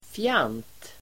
Uttal: [fjan:t]